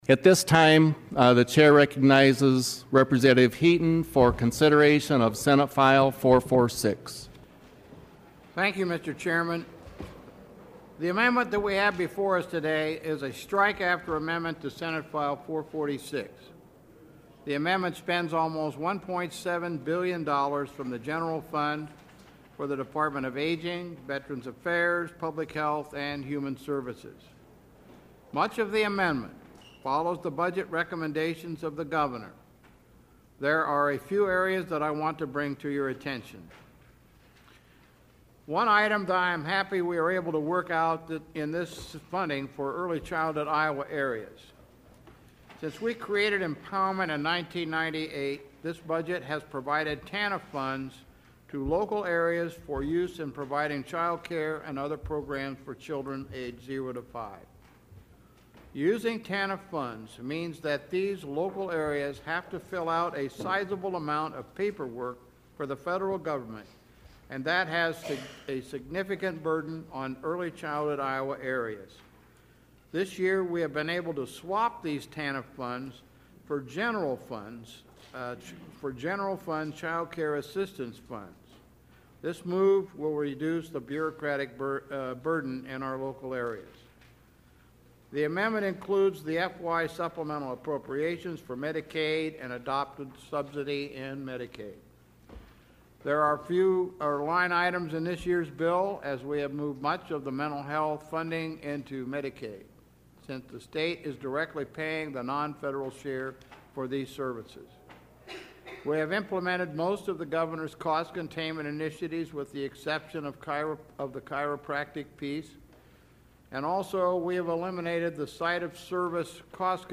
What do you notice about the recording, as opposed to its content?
The debate on this subject happened as members of the House Appropriations Committee considered a bill that outlines next year’s budget for the Iowa Departments of Human Services and Public Health.